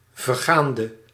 Ääntäminen
Ääntäminen Tuntematon aksentti: IPA: /ˈvɛrˌɣaːndə/ IPA: /vərˈɣaːndə/ Haettu sana löytyi näillä lähdekielillä: hollanti Käännöksiä ei löytynyt valitulle kohdekielelle.